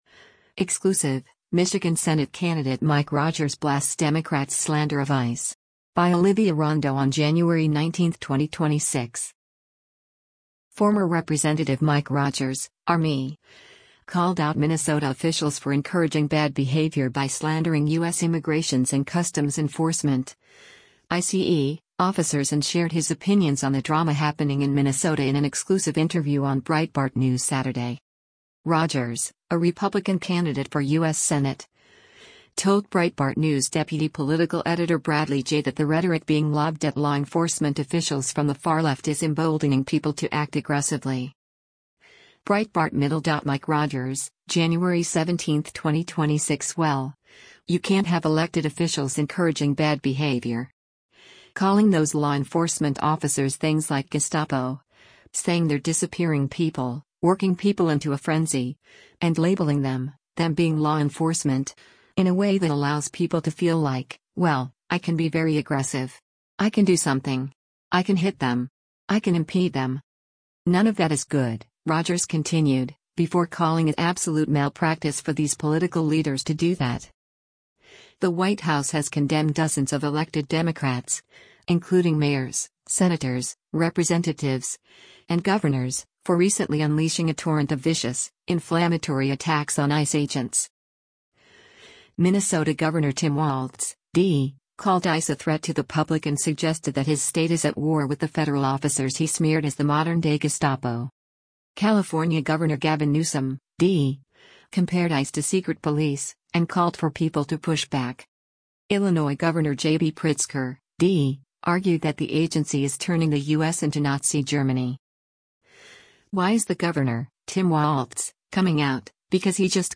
Former Rep. Mike Rogers (R-MI) called out Minnesota officials for “encouraging bad behavior” by slandering U.S. Immigrations and Customs Enforcement (ICE) officers and shared his opinions on the drama happening in Minnesota in an exclusive interview on Breitbart News Saturday
Breitbart News Saturday airs on SiriusXM Patriot 125 from 10:00 a.m. to 1:00 p.m. Eastern.